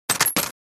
Throw-metal-in-the-trash.mp3